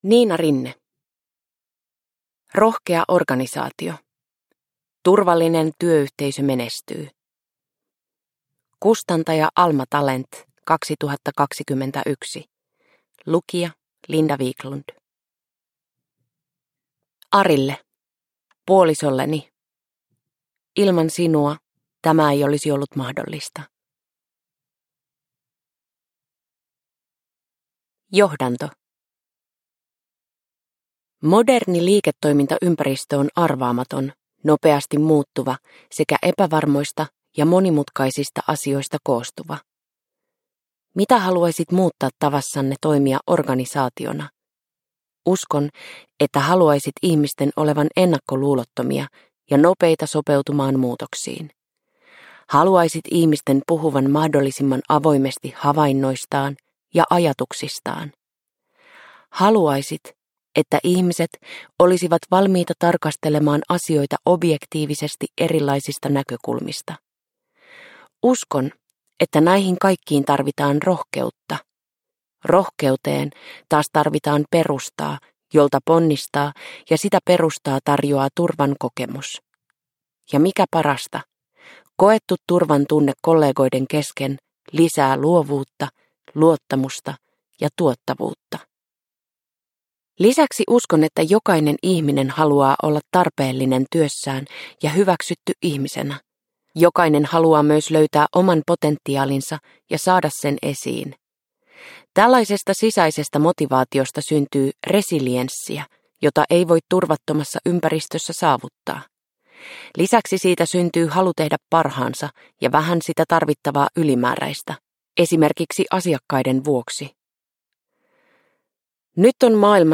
Rohkea organisaatio – Ljudbok – Laddas ner